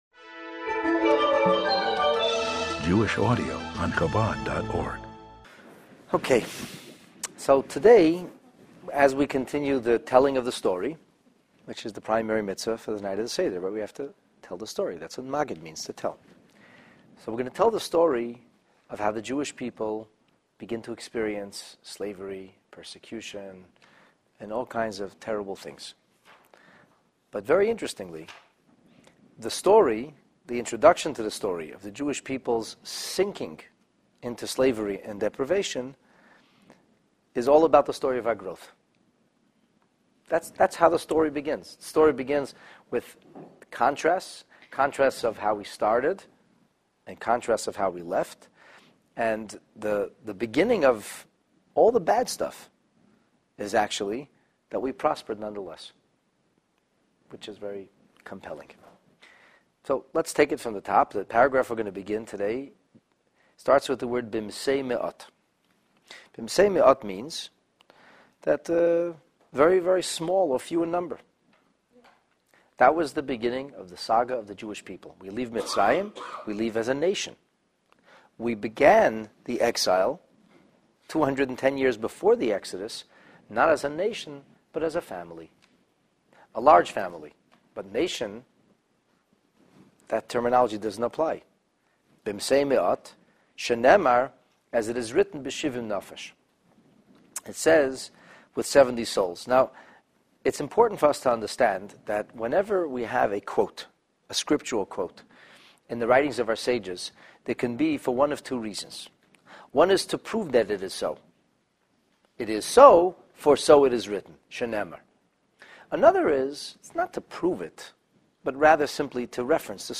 The Haggadah in Depth Part 8 In telling the story of the Jews sinking into slavery and deprivation in Egypt, we preface in the Haggadah how in the beginning, despite all odds, we prospered and experienced tremendous growth. This class covers the paragraphs that beginss "Few in number…" till the passage of "And numerous…,"